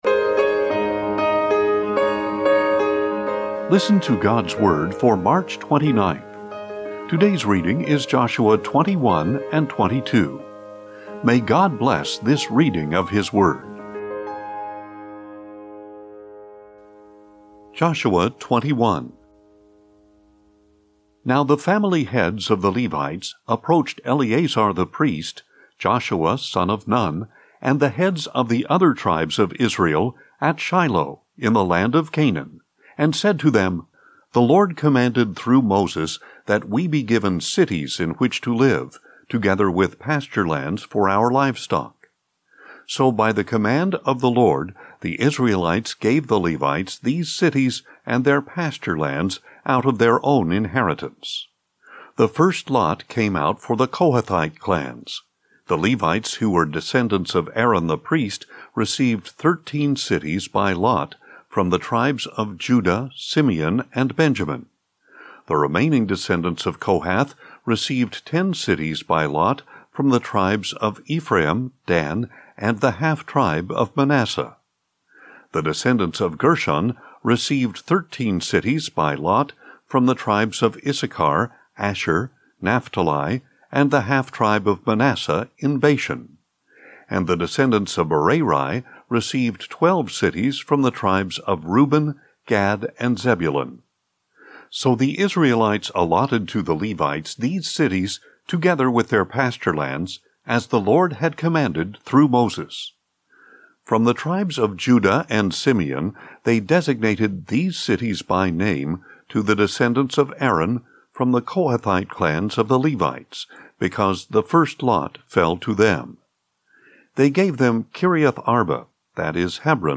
Daily Bible Reading for March 29